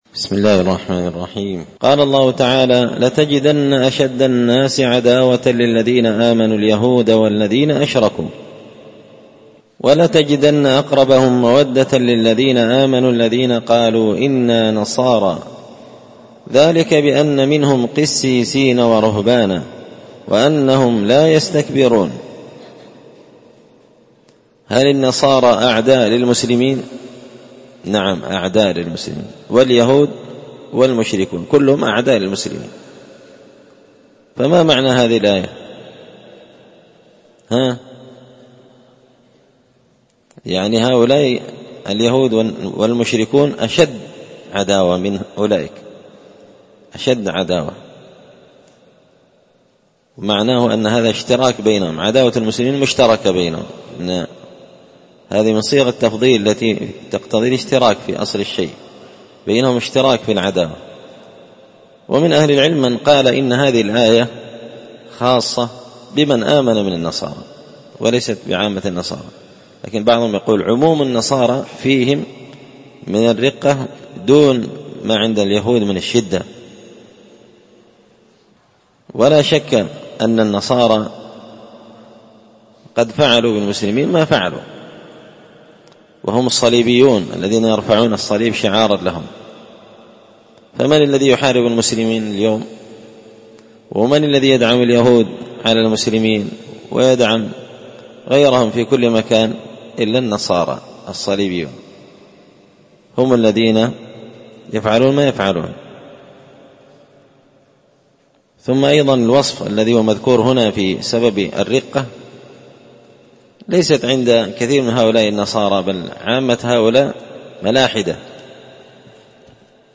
مختصر تفسير الإمام البغوي رحمه الله الدرس 275